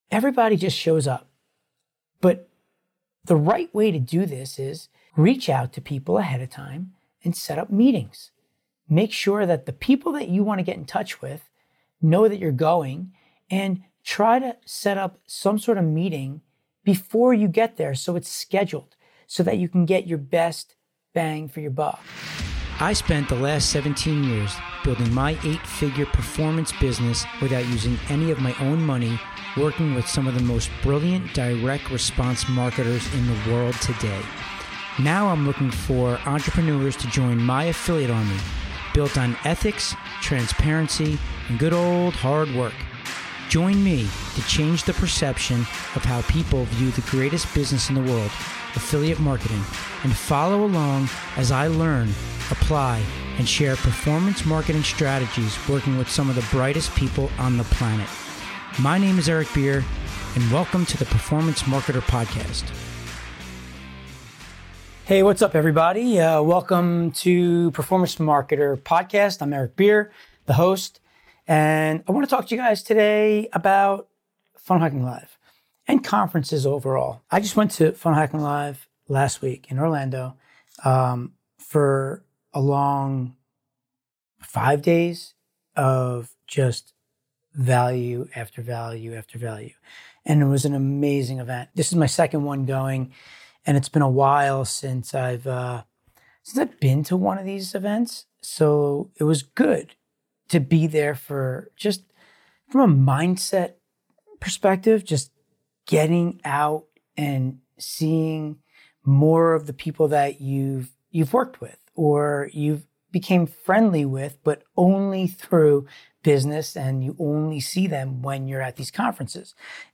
Another week, another awesome interview!